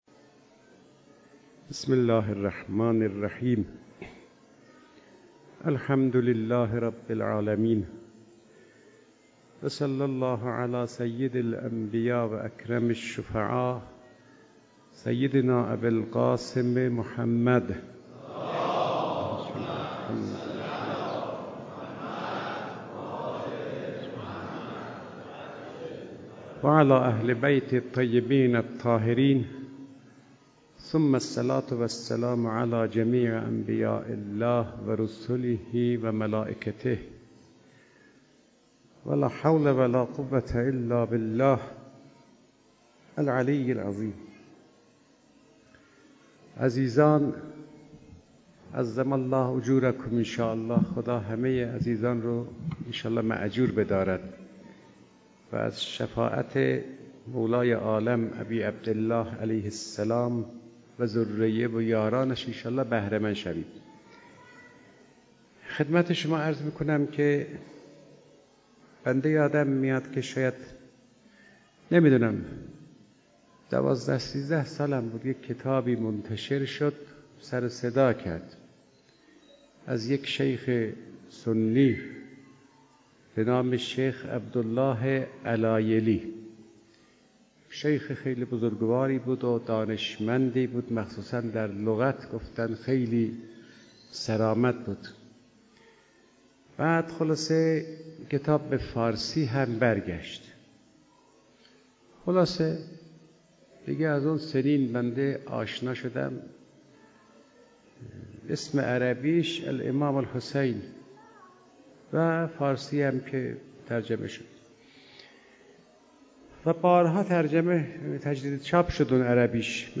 سخنرانی استاد فاطمی نیا با موضوع فضائل انسانی از دیدگاه اهل بیت (ع) - محرم 1395 برای دانلود این مجموعه سخنرانی ارزشمند به ادامه مطلب مراجعه کنید.